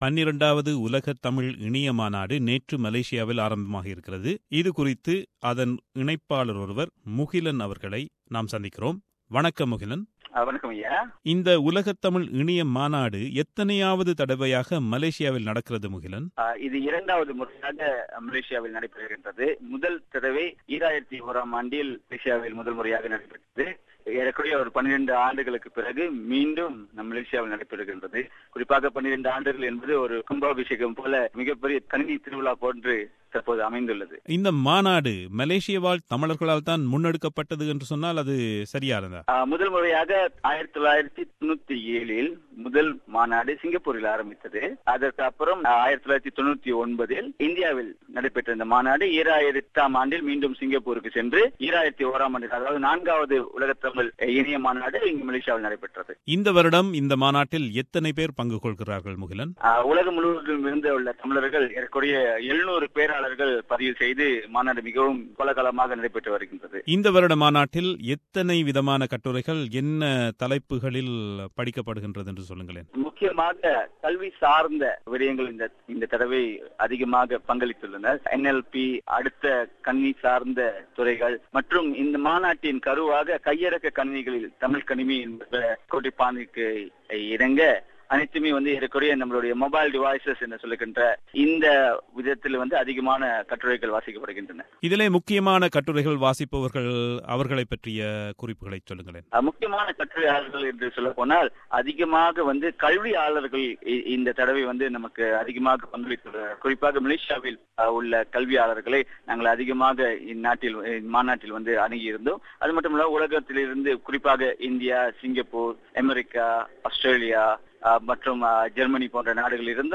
மலேசியாவில் நடைபெறும் உலகத் தமிழ் இணைய மாநாடு குறித்த ஒரு நேர்காணல்.